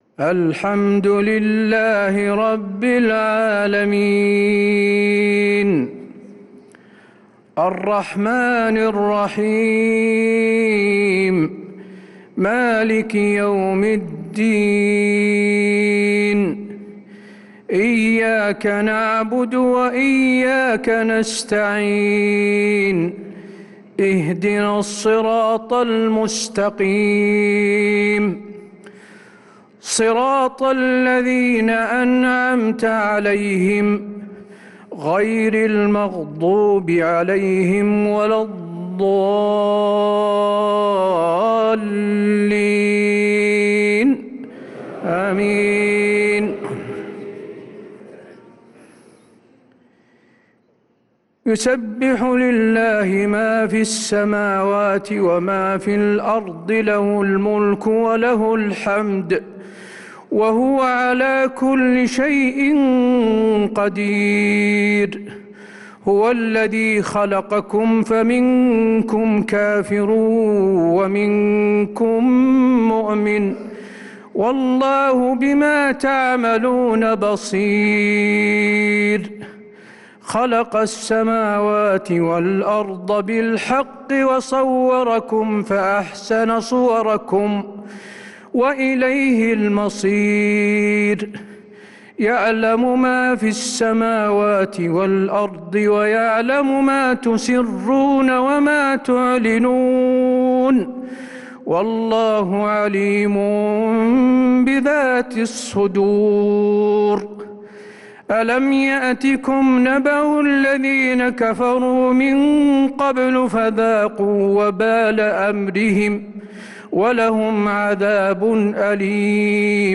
عشاء الأحد 2-9-1446هـ سورة التغابن كاملة | Isha prayer Surat at-Taghabun 2-3-2025 > 1446 🕌 > الفروض - تلاوات الحرمين